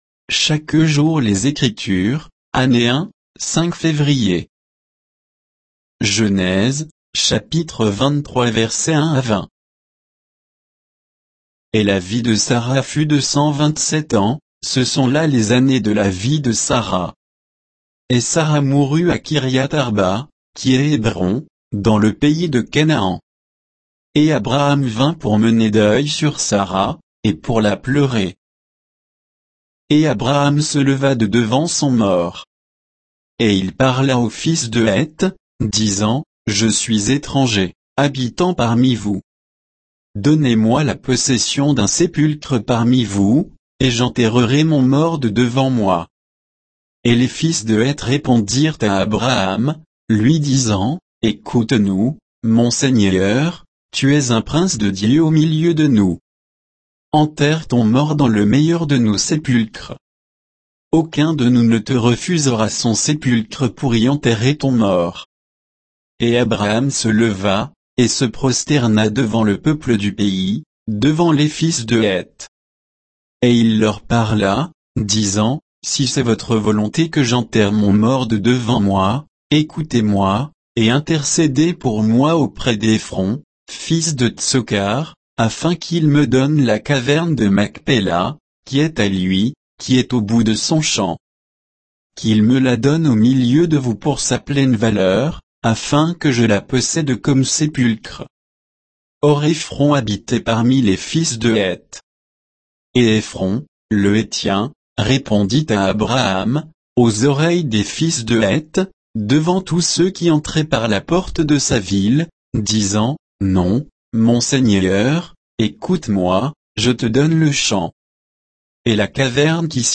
Méditation quoditienne de Chaque jour les Écritures sur Genèse 23, 1 à 20